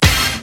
SPLASH HIT.wav